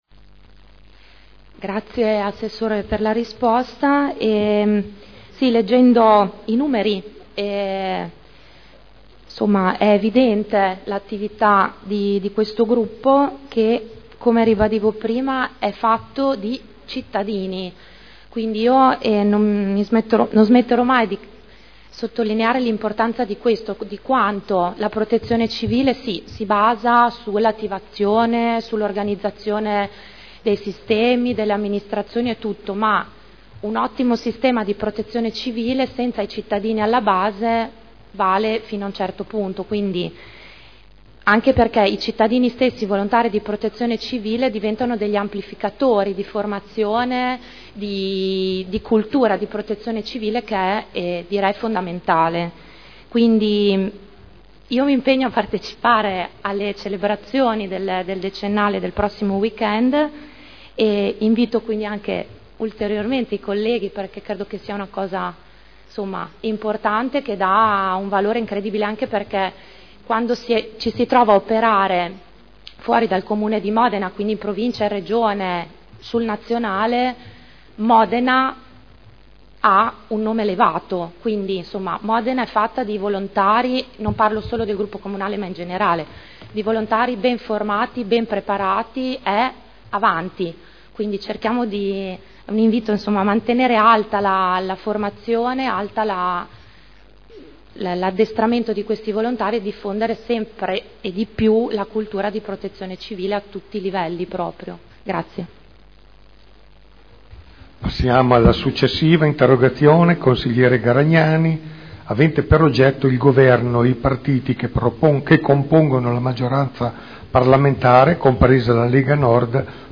Elisa Sala — Sito Audio Consiglio Comunale